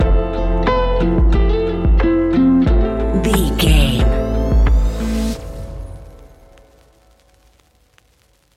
Ionian/Major
A♭
laid back
Lounge
sparse
new age
chilled electronica
ambient
atmospheric
morphing